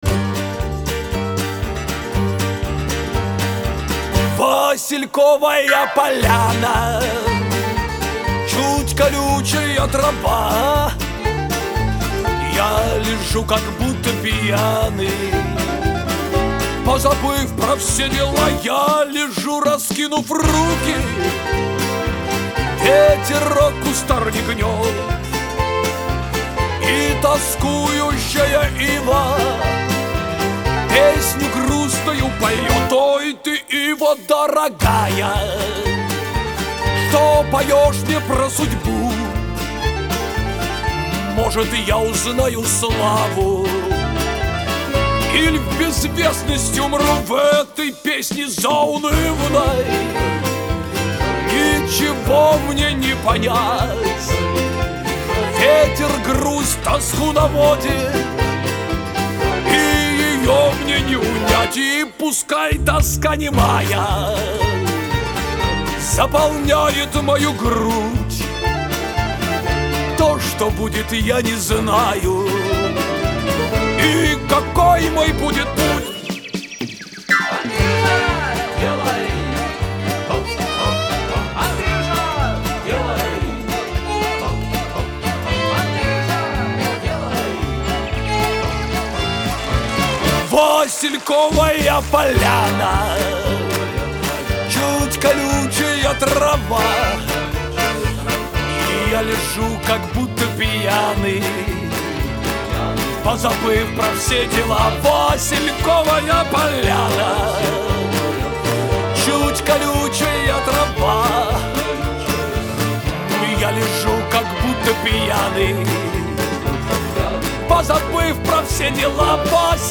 Вот ИМЕННО ТАКОЙ вариант я и помню.